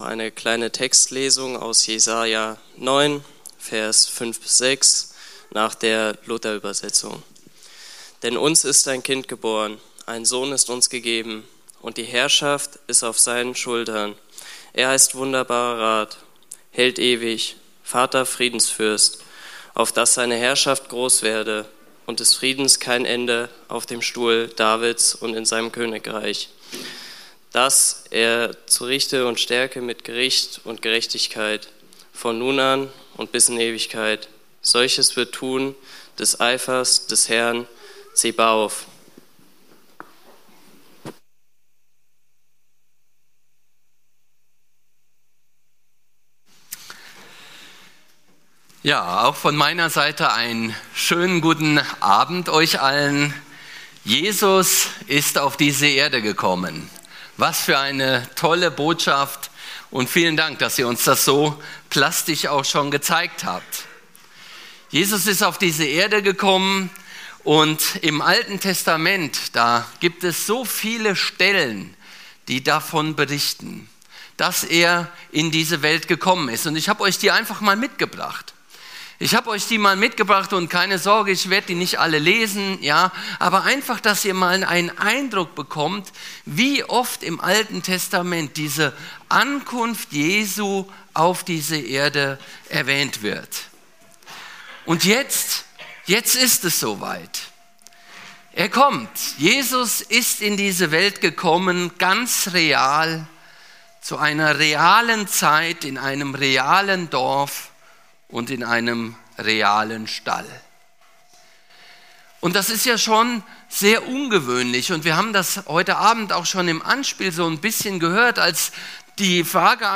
24.12.2025 Heiligabend ~ Predigten - FeG Steinbach Podcast